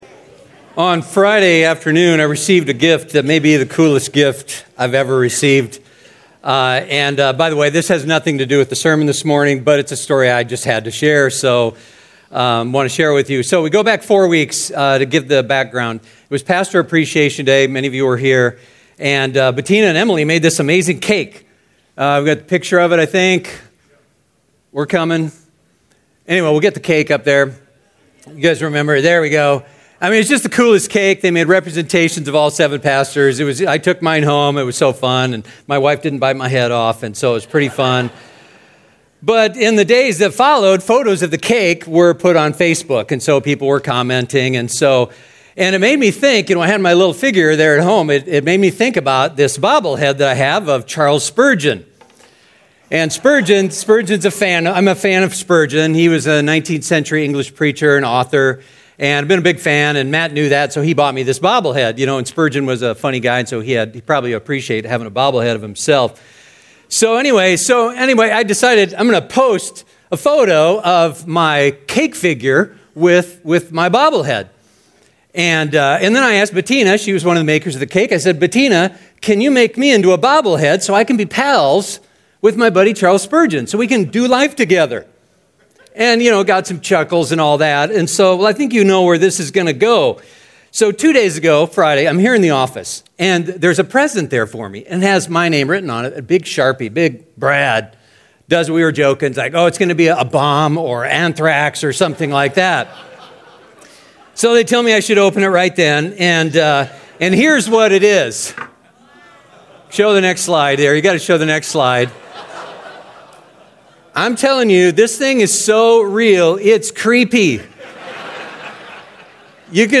Series: David's Psalms: Mirror of the Soul SERMON